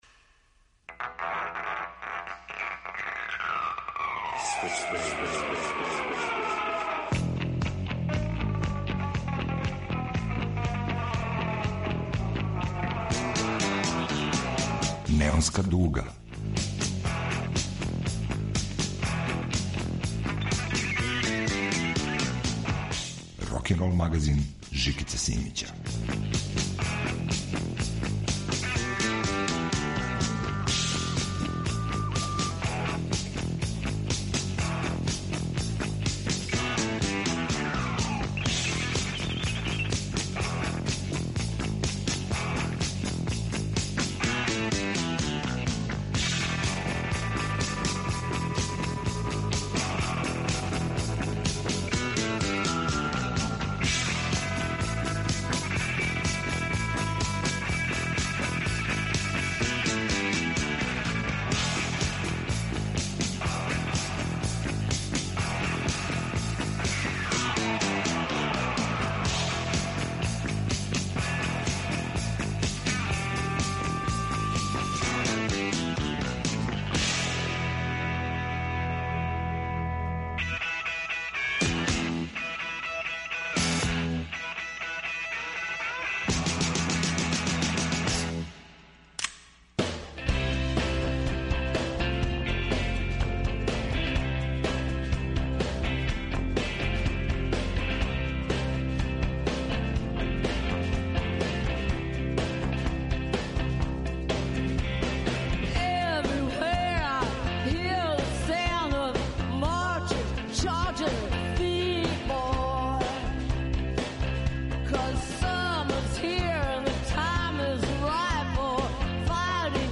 рокенрол магазин